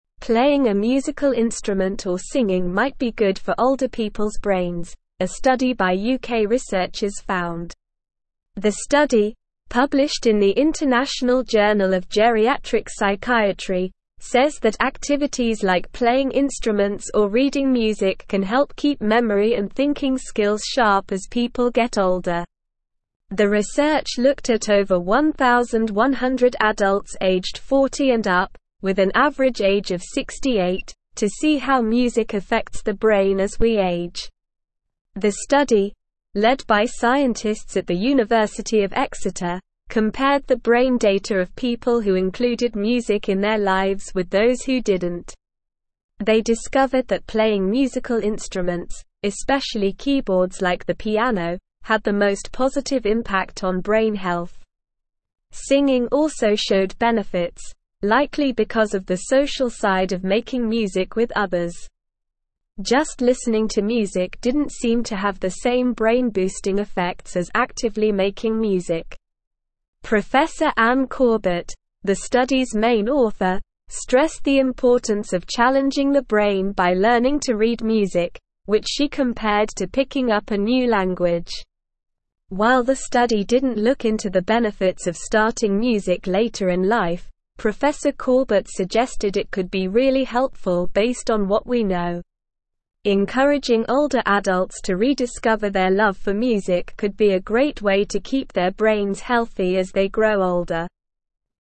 Slow
English-Newsroom-Upper-Intermediate-SLOW-Reading-Music-and-Brain-Health-Benefits-of-Playing-Instruments.mp3